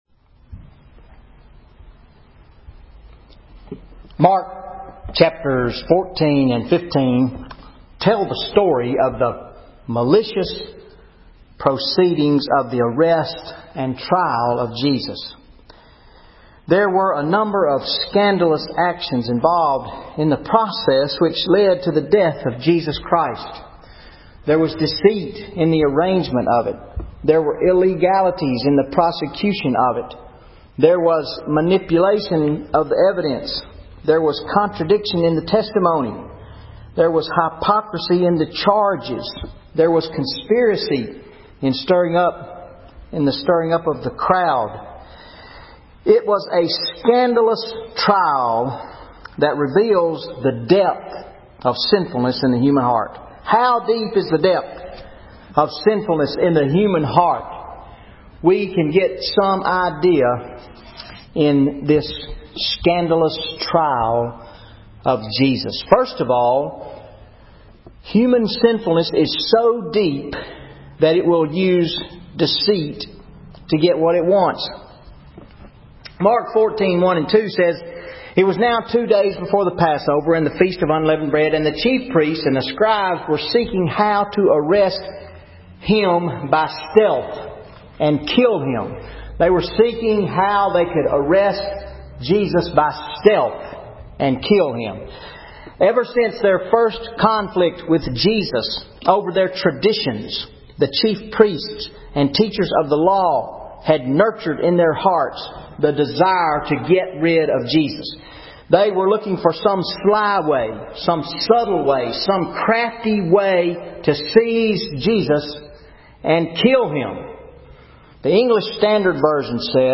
Sunday Sermon July 28, 2013 Mark 15:1-20 How Deep is Depth of Human Sinfulness? – First Presbyterian Church
Sermon Audio